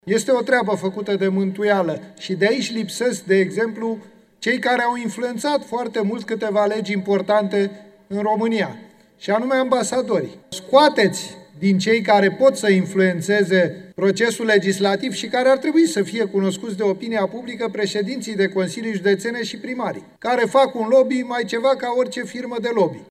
Petrișor Peiu, Senator AUR: „Este o treabă făcută de mântuială”